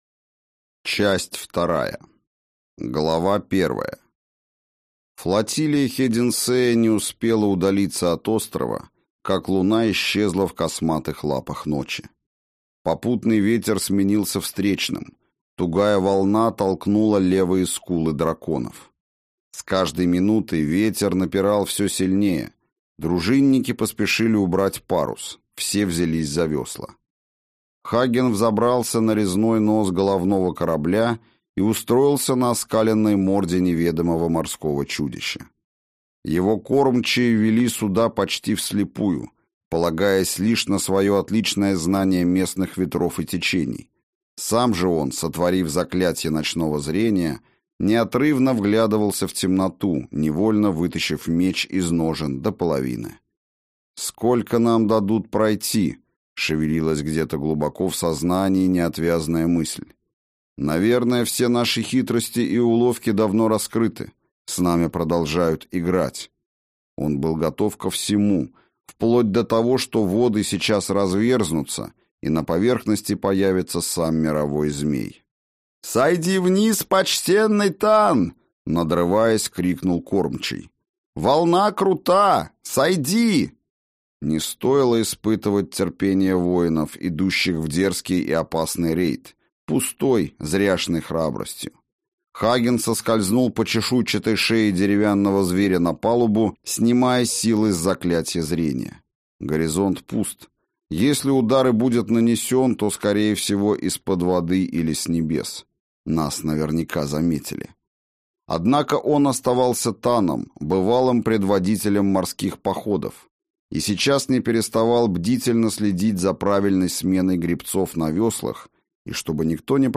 Аудиокнига Гибель богов (Книга Хагена). Часть 2 | Библиотека аудиокниг